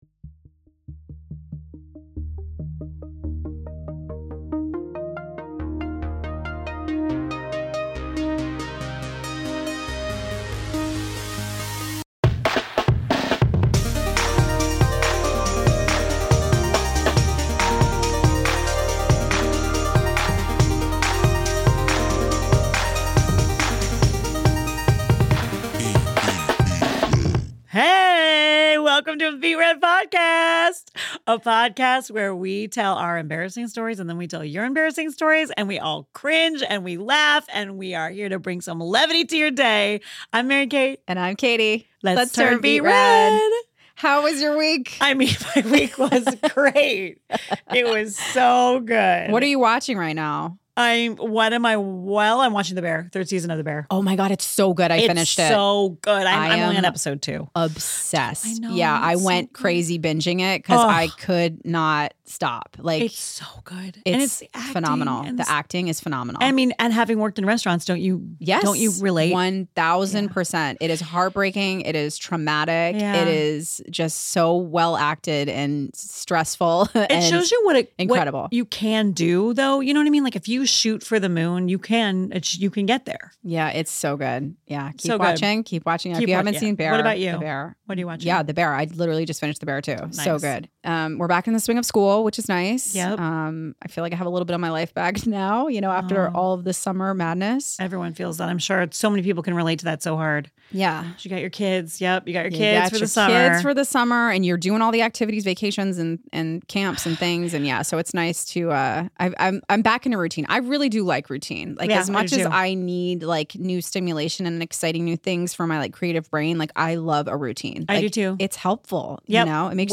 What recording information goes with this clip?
PROJKT studios in Monterey Park, CA